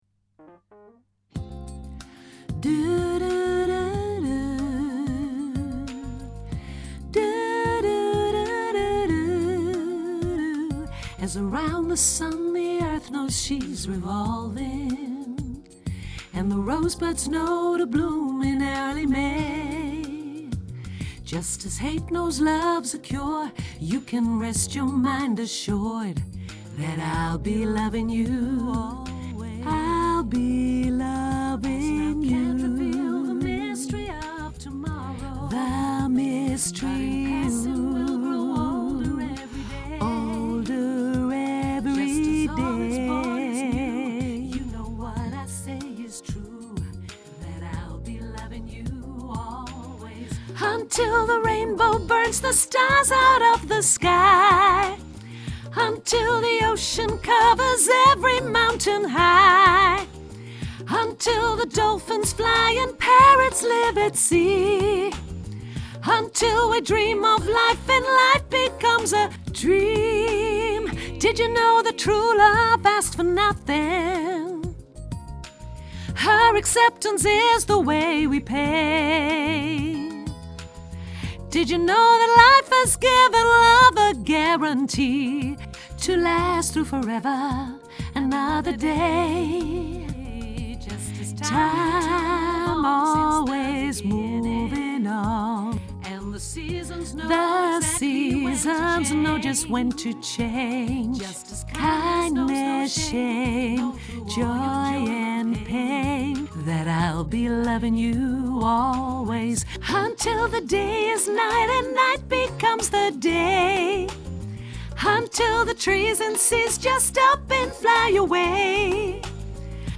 mezzo sopraan